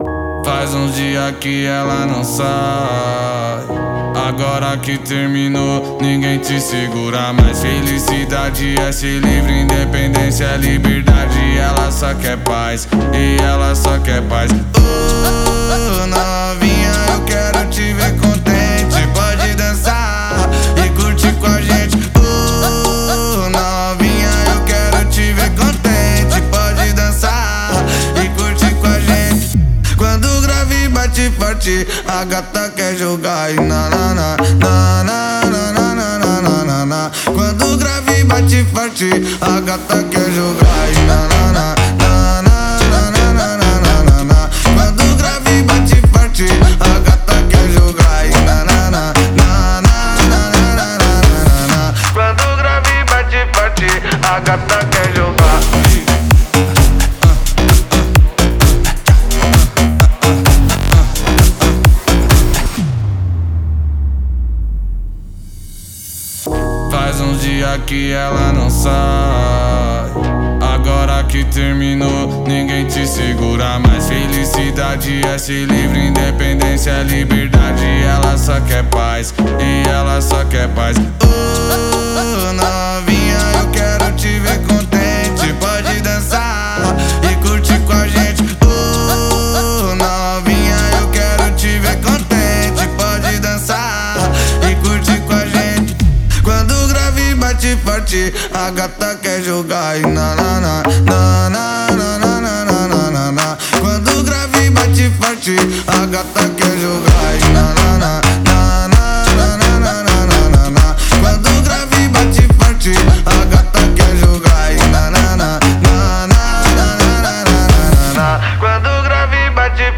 это зажигательный трек в жанре бразильского фанк и EDM